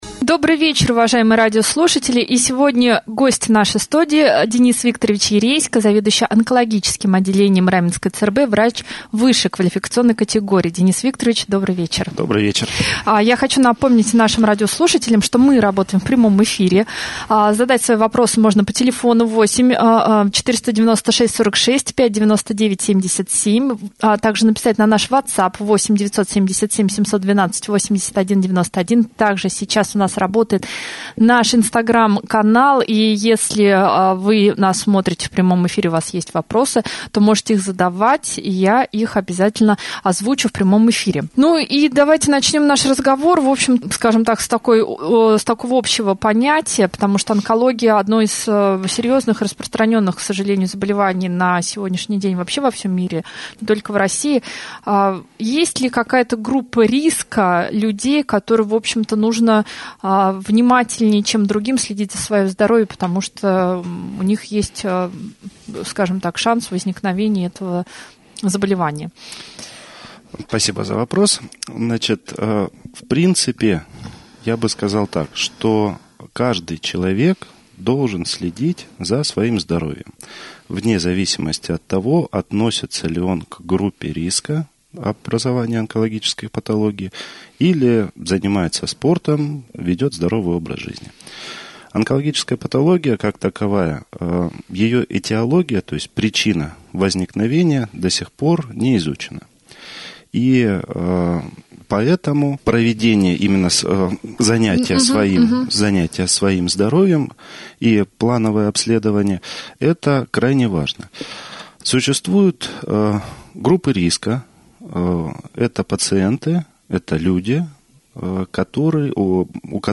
Еще мы обсудили малоинвазивные операции, современное оборудование и ответили на вопросы радиослушателей.